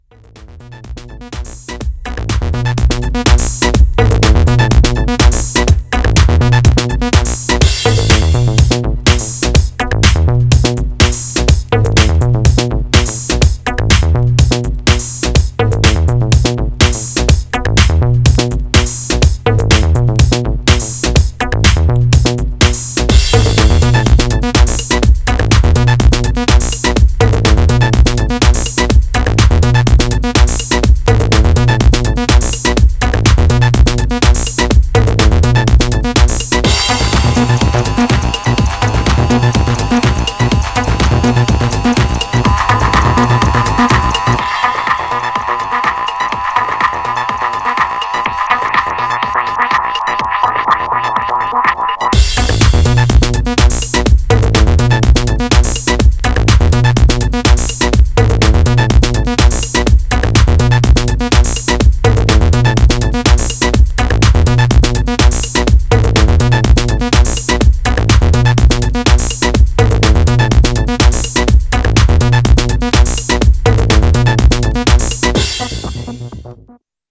Styl: Electro, House, Techno